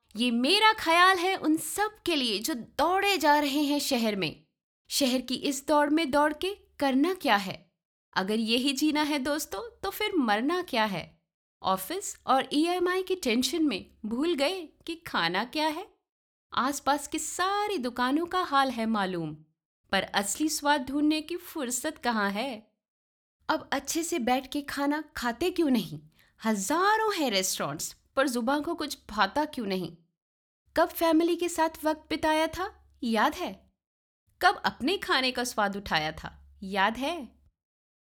Bank Of Montreal Voice Over Commercial Actor + Voice Over Jobs
Standard Tokyo accent. 20s to 50s baritone range.